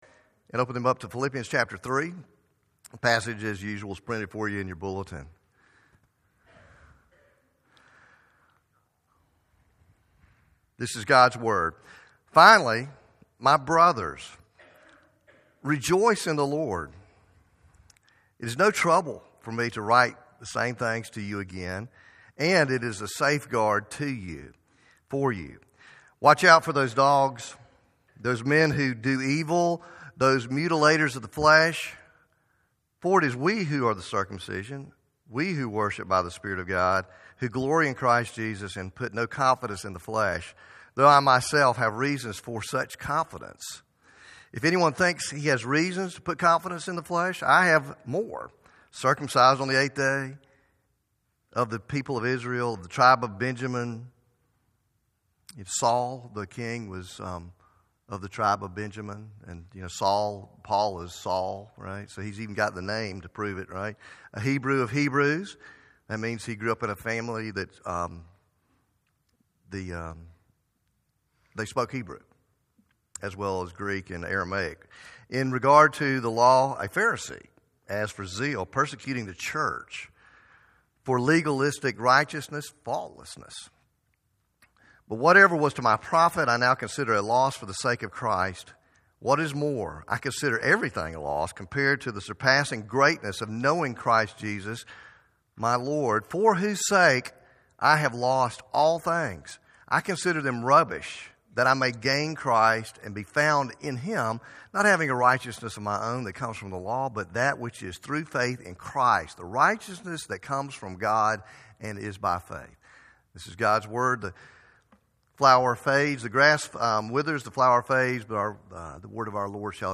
Faith Presbyterian Church PCA Sermons